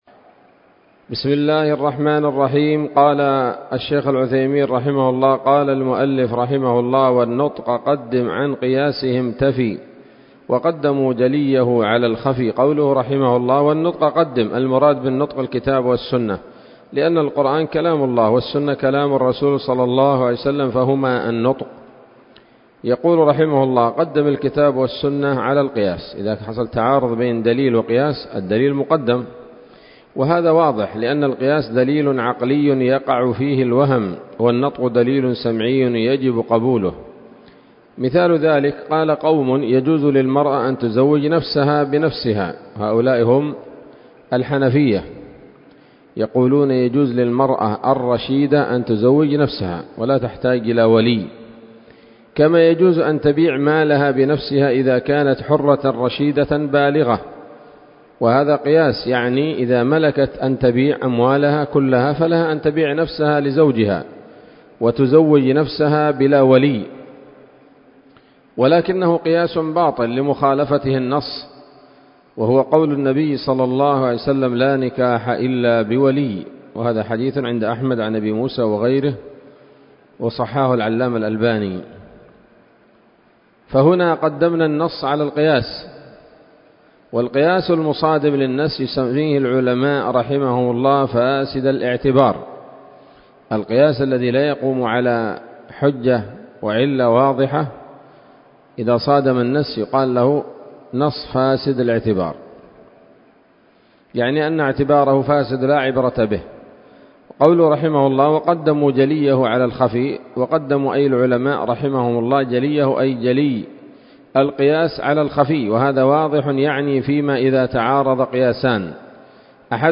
الدرس السادس والستون من شرح نظم الورقات للعلامة العثيمين رحمه الله تعالى